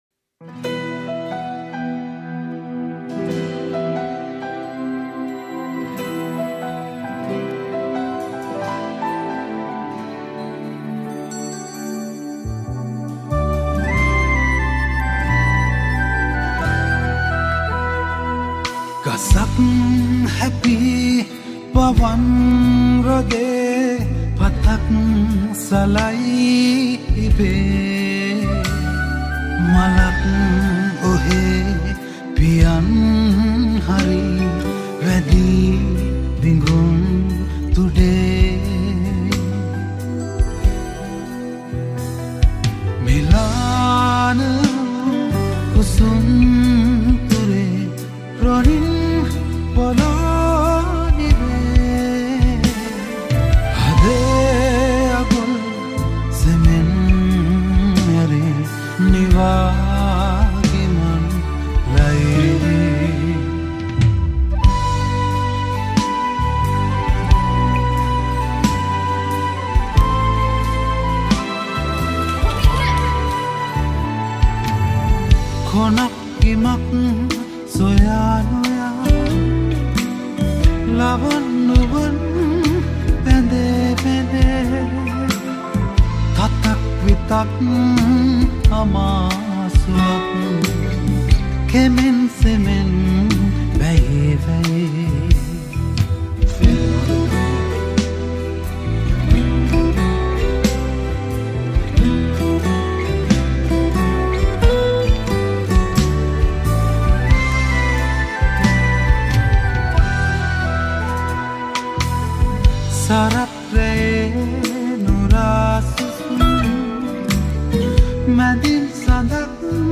All Keys
Guitars